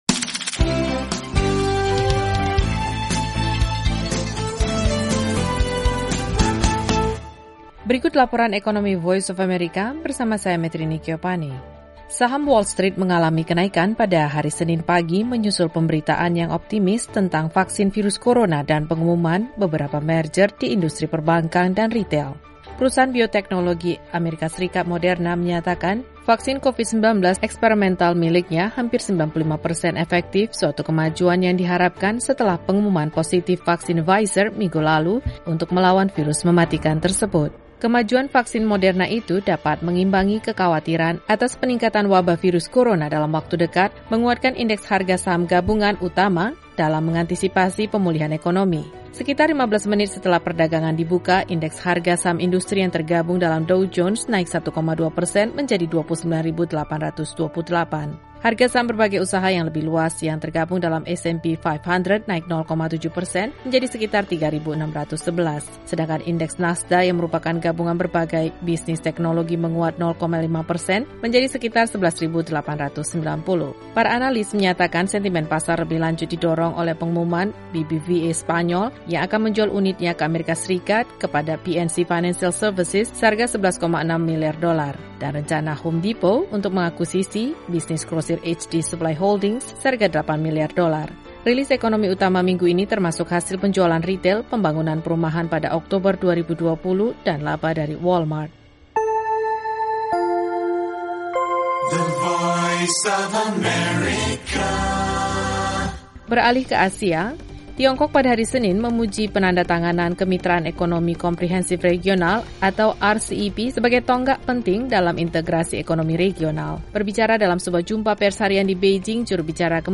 Info Ekonomi